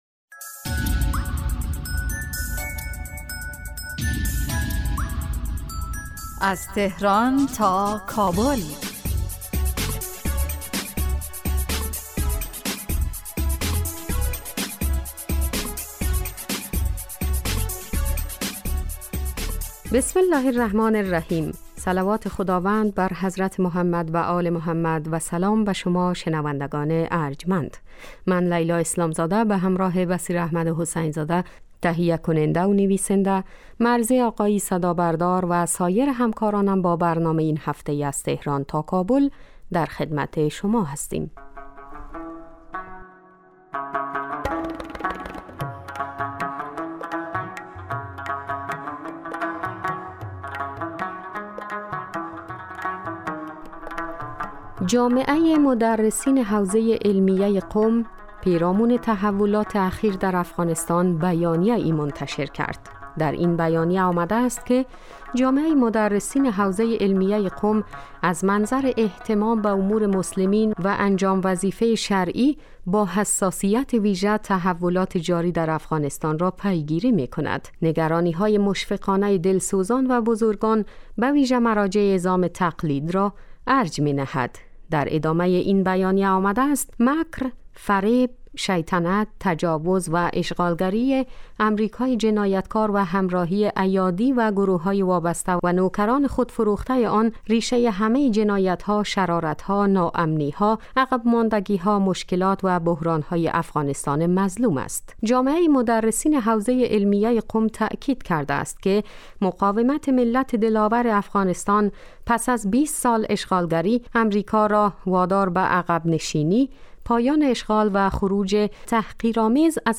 برنامه از تهران تا کابل به مدت 15 دقیقه روز جمعه در ساعت 17:30 پخش می شود. این برنامه به رویدادهای سیاسی، فرهنگی، اقتصادی و اجتماعی مشترک ایران و افغانستان می پردازد.